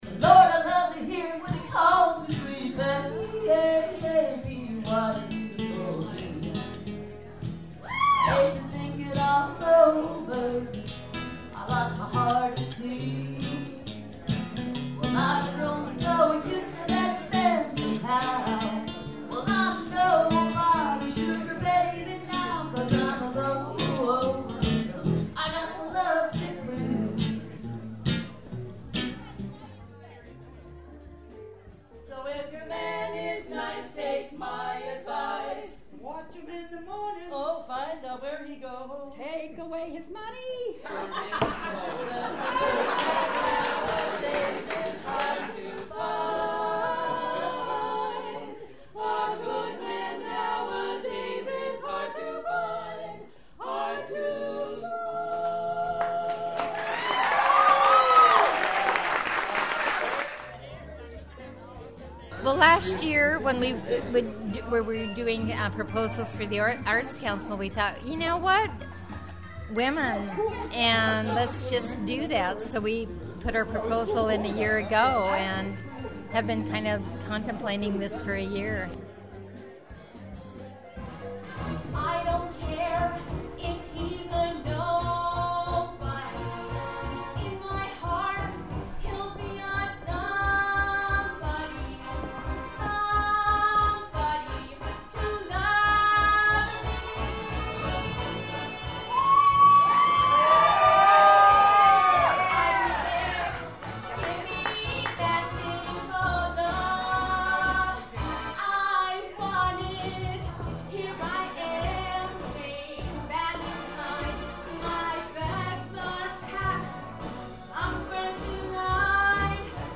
The three-day celebration for “Passages, the Art of Being a Woman” kicked off Friday with the gallery show opening, continued Saturday with a musical gala performance at Creekside Cabaret, and wrapped up on Sunday with a poetry reading at O’Brien’s Pub.
Here’s an audio postcard from the Saturday night performance: